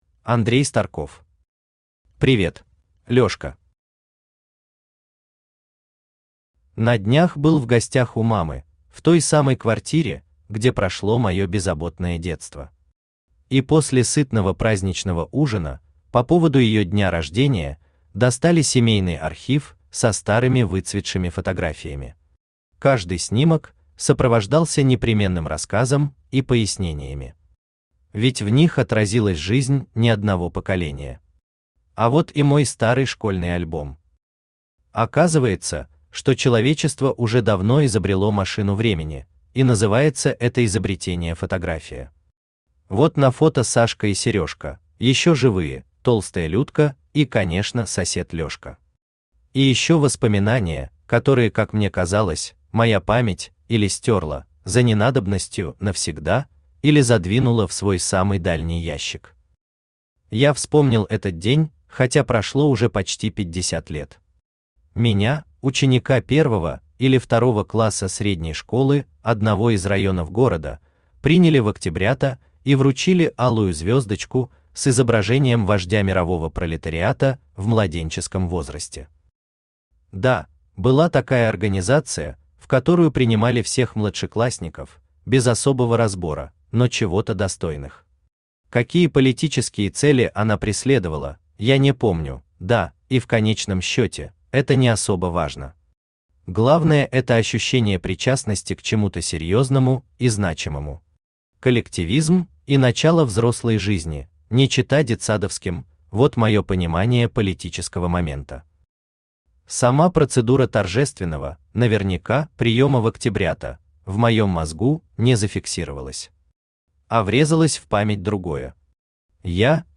Aудиокнига Привет Автор Андрей Старков Читает аудиокнигу Авточтец ЛитРес.